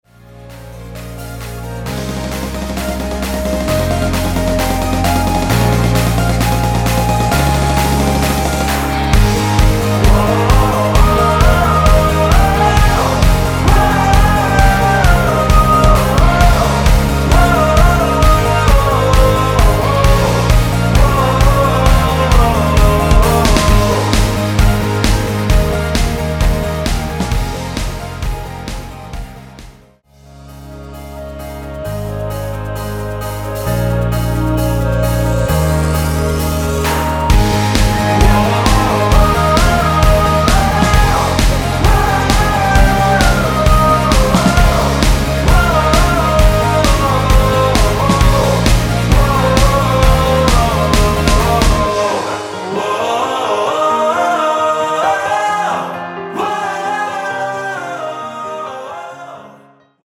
원키에서(-1)내린 멜로디와 코러스 포함된 MR입니다.(미리듣기 확인)
Ab
앞부분30초, 뒷부분30초씩 편집해서 올려 드리고 있습니다.
중간에 음이 끈어지고 다시 나오는 이유는